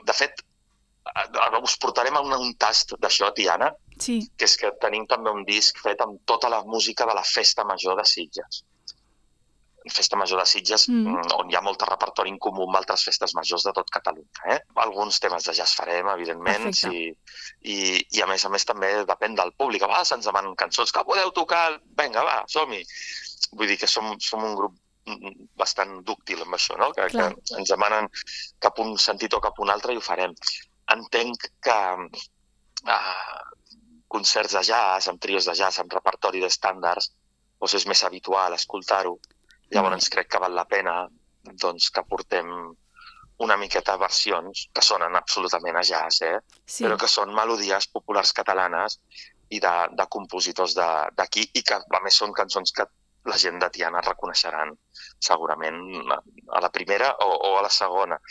amb qui fem l’entrevista.